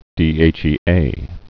(dēāchēā)